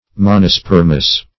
Search Result for " monospermous" : The Collaborative International Dictionary of English v.0.48: Monospermal \Mon`o*sper"mal\, Monospermous \Mon`o*sper"mous\, a. [Mono- + Gr. spe`rma seed: cf. F. monosperme.]